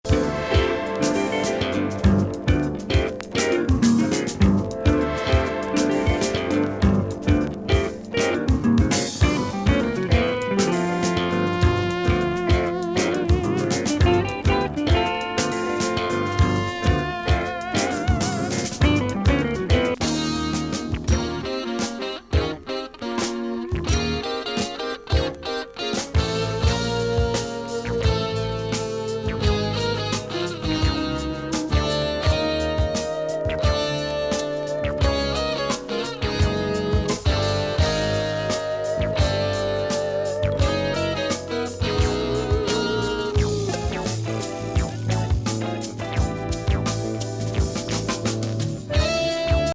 ムーグ、エレピが効いた